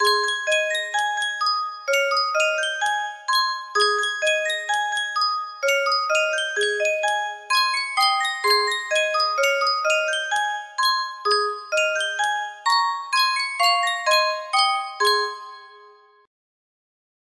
Sankyo Music Box - Good King Wenceslas GAI music box melody
Full range 60